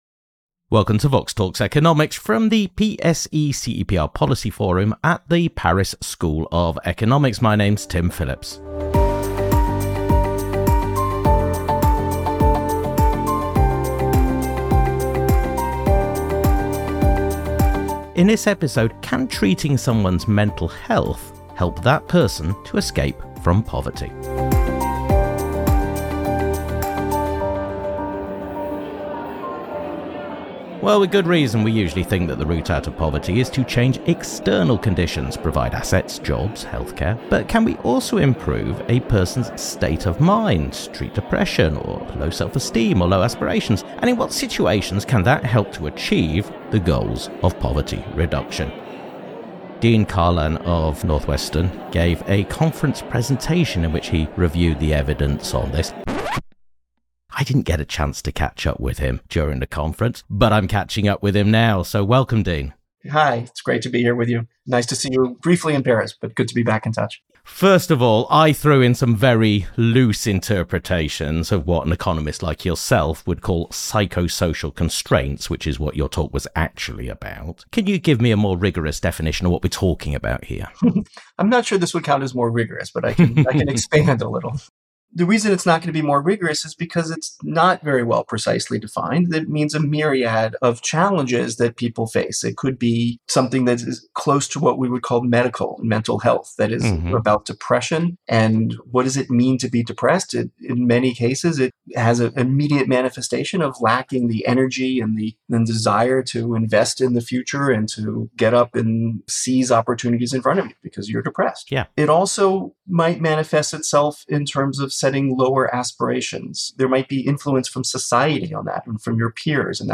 From the PSE-CEPR Policy Forum at the Paris School of Economics.